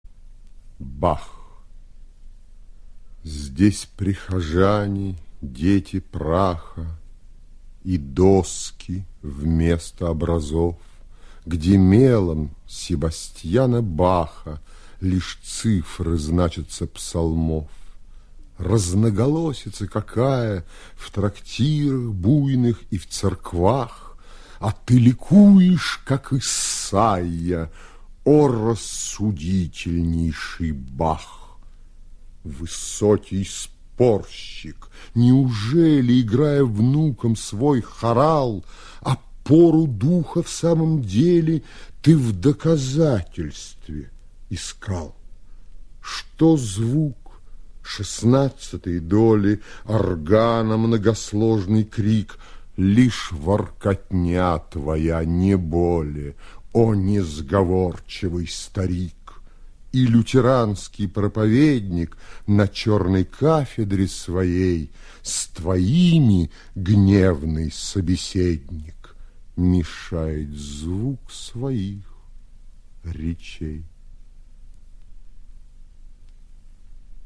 Mandelshtam-Bah-1913-chitaet-Mihail-Kozakov-stih-club-ru.mp3